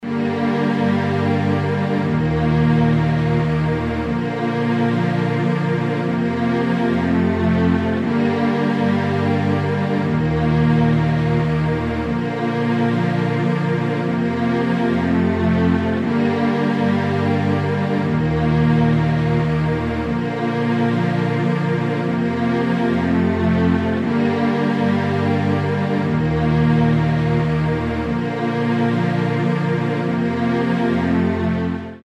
Sad Music.mp3